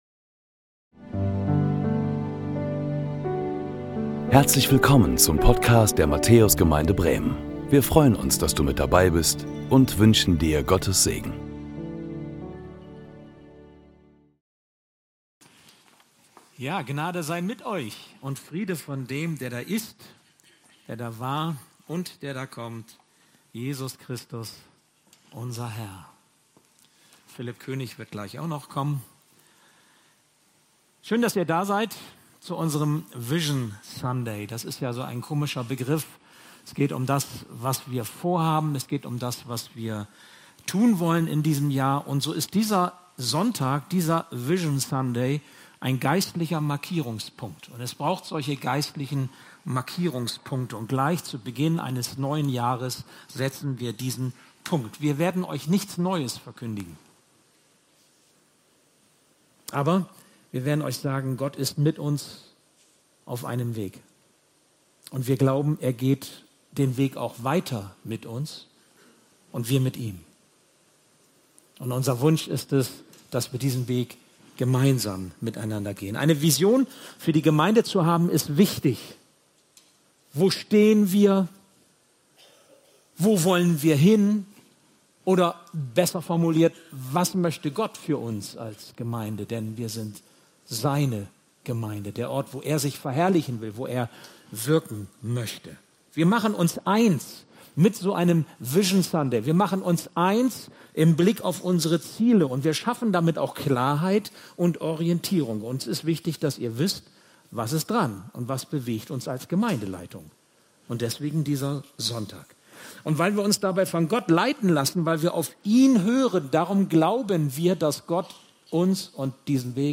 Predigten der Matthäus Gemeinde Bremen Vision Sunday 2026 Play Episode Pause Episode Mute/Unmute Episode Rewind 10 Seconds 1x Fast Forward 30 seconds 00:00 / 00:23:43 Abonnieren Teilen Apple Podcasts RSS Spotify RSS Feed Teilen Link Embed